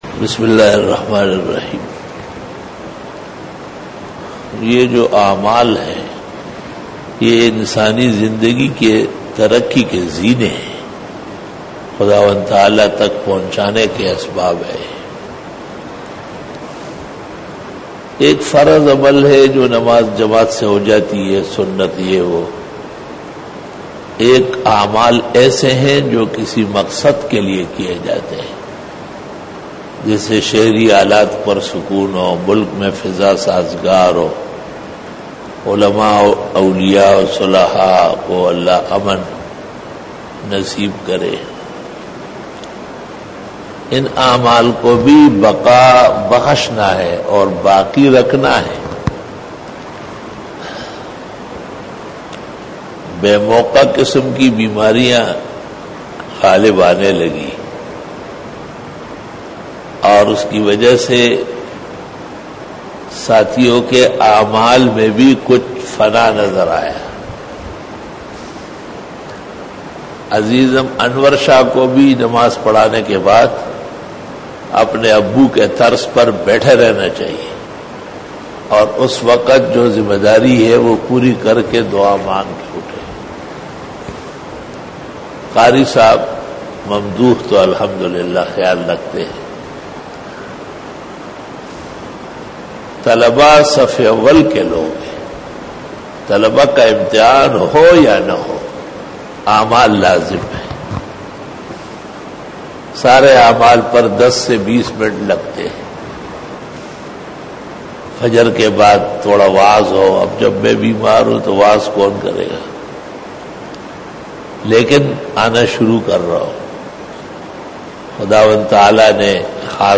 After Isha Byan